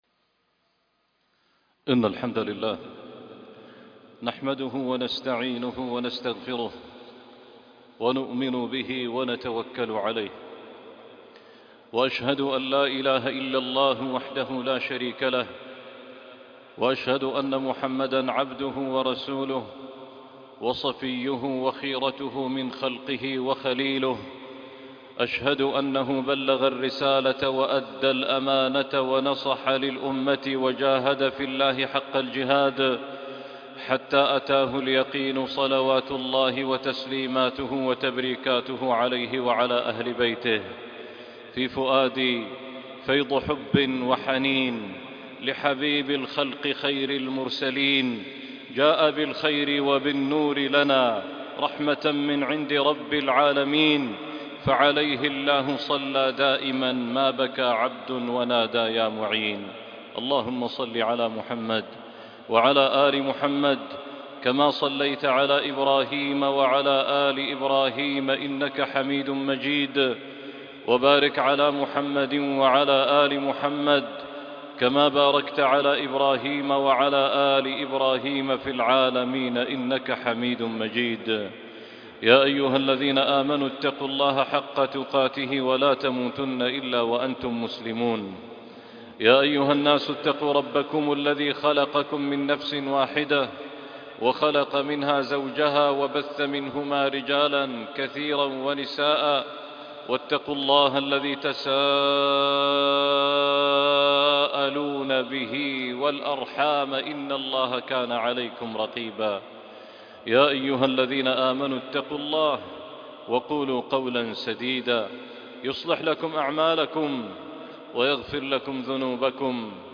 لا أعبد ما تعبدون - خطبة وصلاة الجمعة